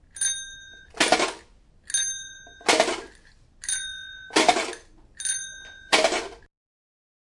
购物 " 内商店收银机 2
描述：商店收银机，用收银机扫描的物品，地点：Riihimaki 芬兰日期：2013年
Tag: 位置 收银机 芬兰 商店 室内